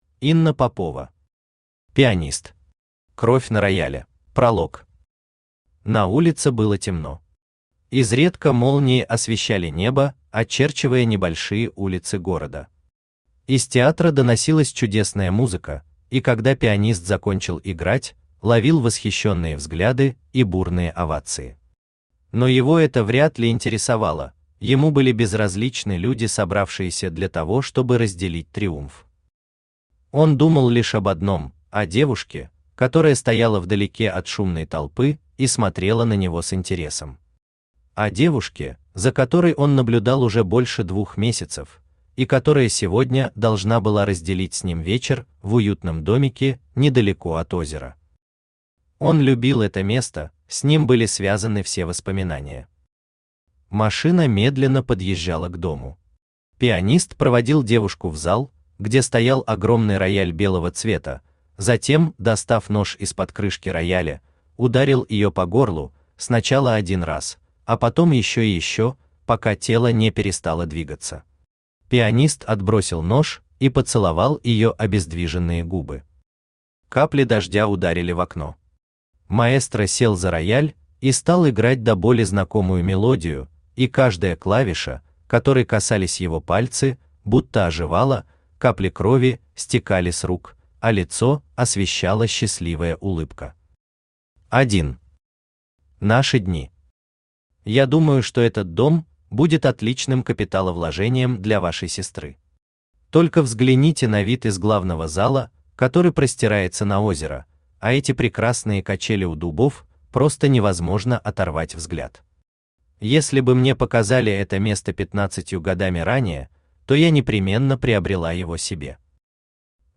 Аудиокнига Пианист. Кровь на рояле | Библиотека аудиокниг
Кровь на рояле Автор Инна Борисовна Попова Читает аудиокнигу Авточтец ЛитРес.